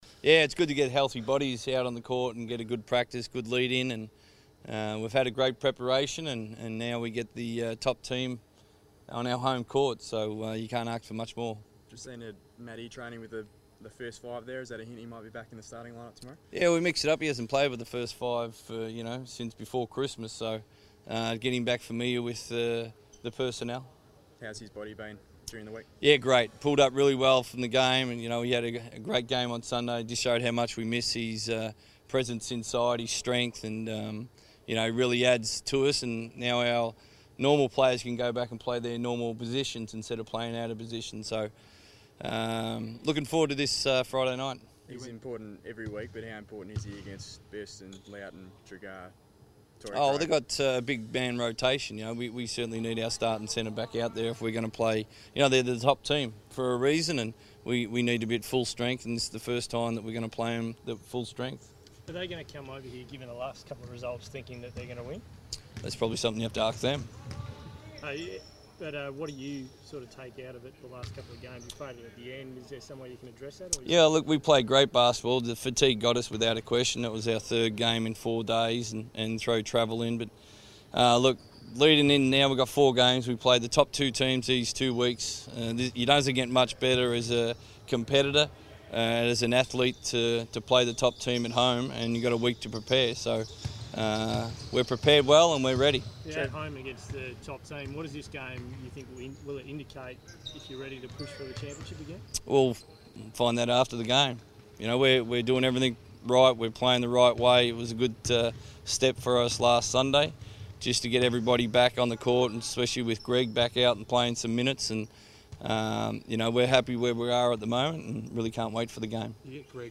Trevor Gleeson press conference - 5 February 2015
Trevor Gleeson speaks to the media ahead of the Perth Wildcats clash versus the Cairns Taipans at Perth Arena this Friday.